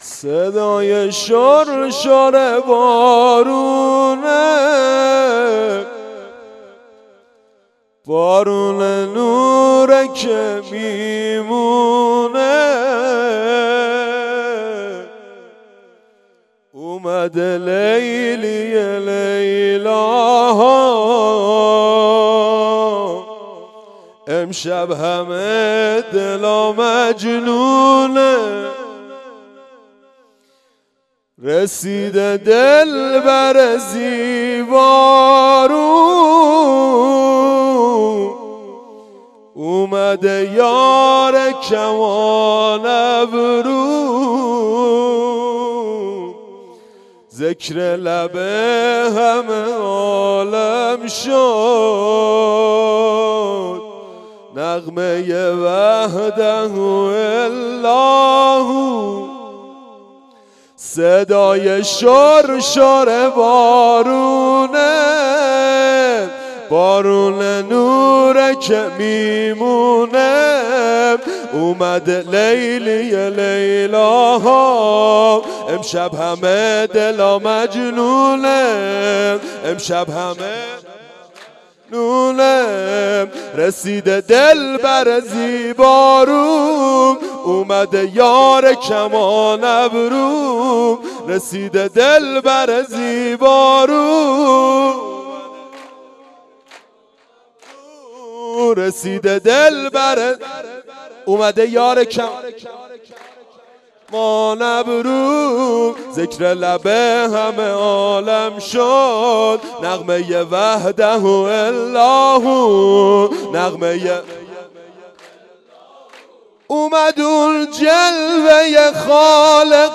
04-veladat-payambar-93-sorood.mp3